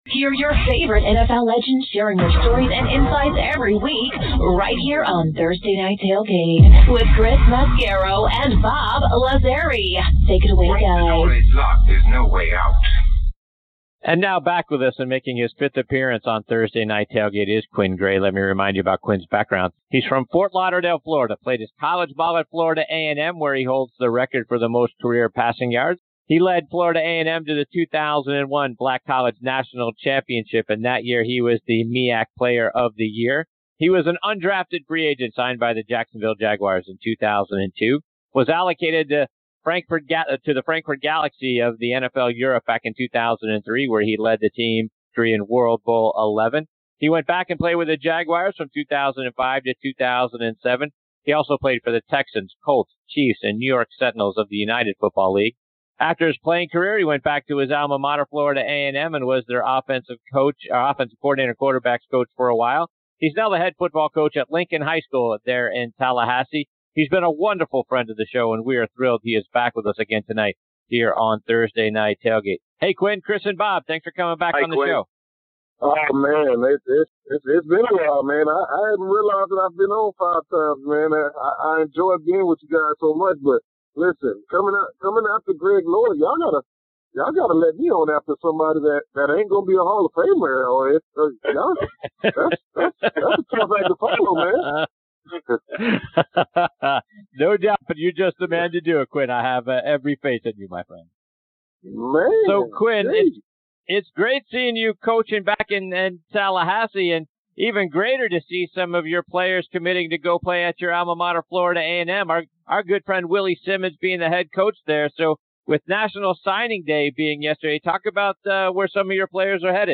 Former Jaguars & Colts QB and current Head Coach at Lincoln High School in Tallahassee Quinn Gray shares his stories and insights on this segment of Thursday Night Tailgate NFL Podcast.